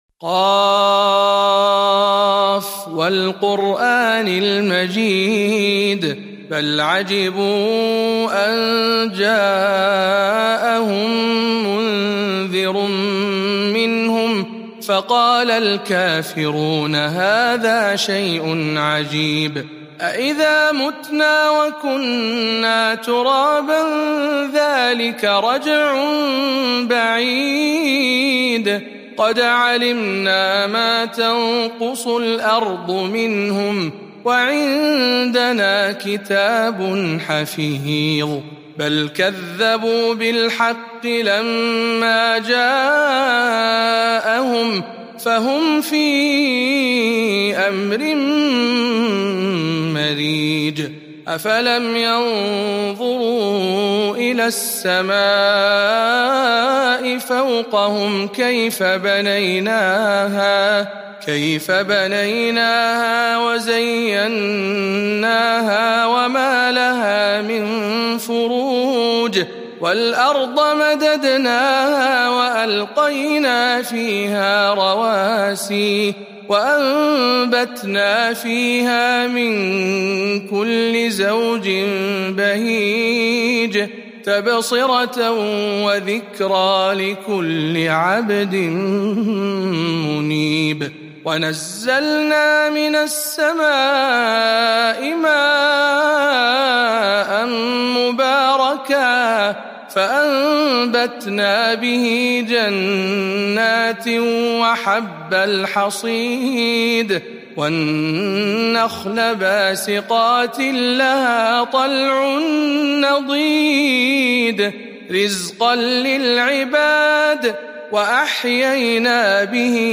049. سورة ق برواية شعبة عن عاصم - رمضان 1441 هـ